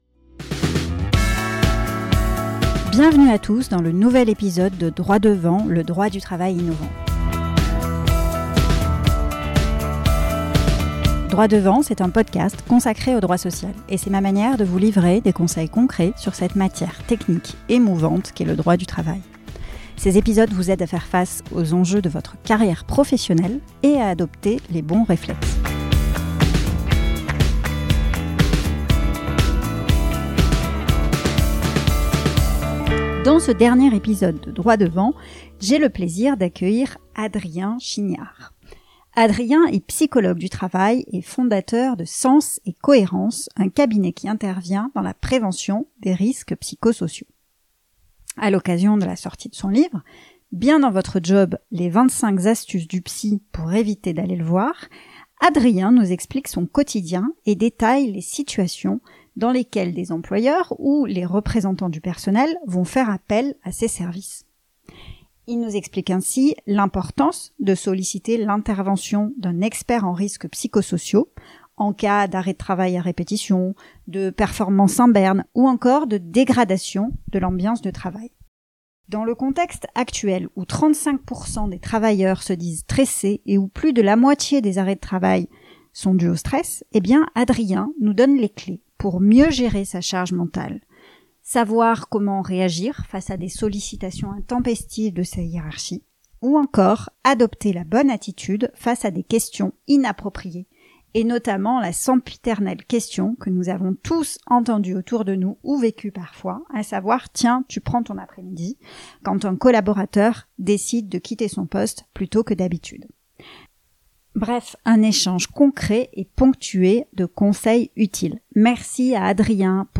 Bref, un échange concret et ponctué de conseils utiles pour adopter les bons réflexes en cas d’ambiance de travail dégradée